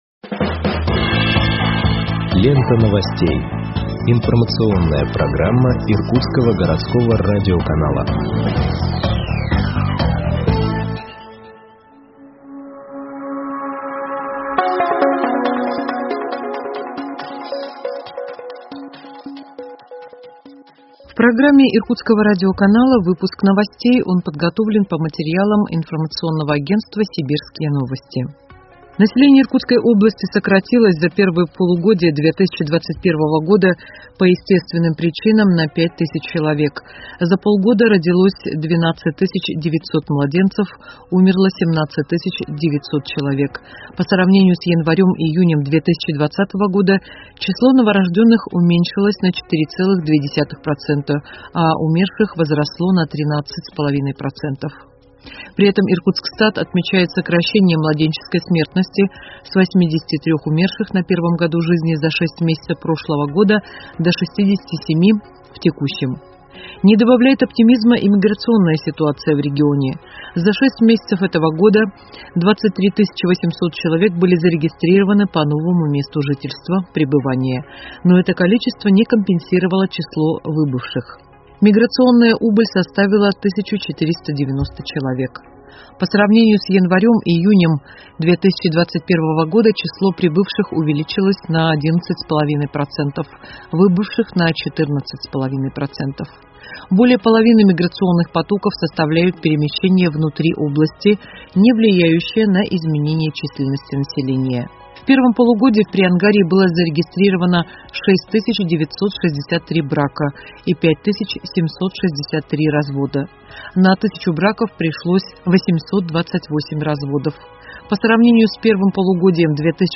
Выпуск новостей в подкастах газеты Иркутск от 09.08.2021 № 1